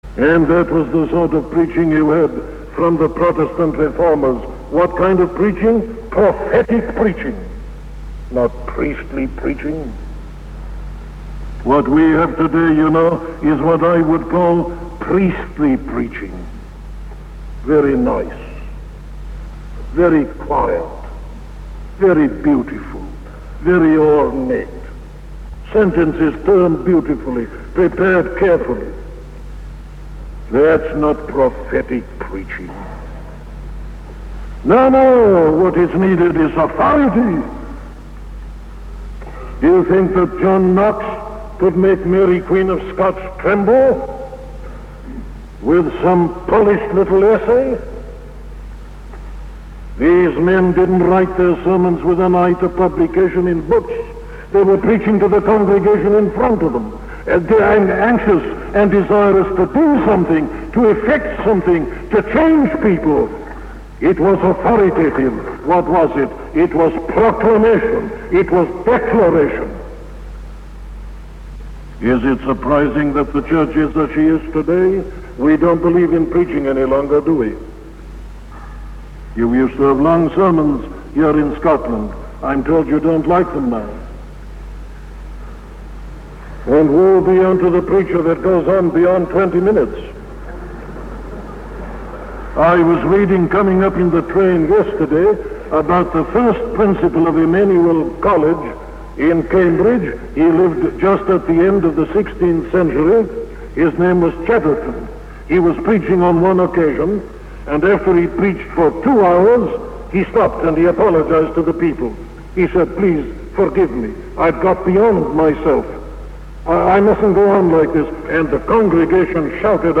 A sermon from D. Martyn Lloyd-Jones